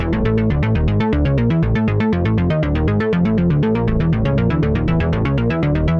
Index of /musicradar/dystopian-drone-samples/Droney Arps/120bpm
DD_DroneyArp2_120-A.wav